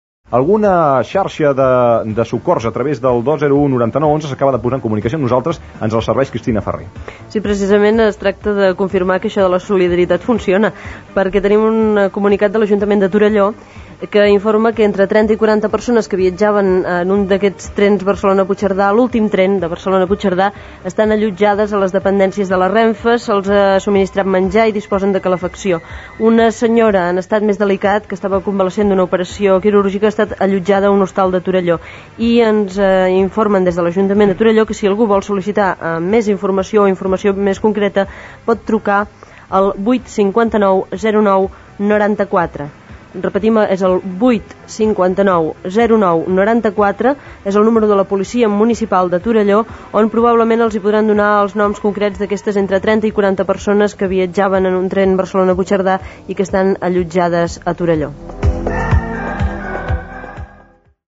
Programa especial dedicat a la gran nevada que cau a Catalunya.
Informatiu